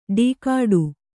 ♪ ḍīkāḍu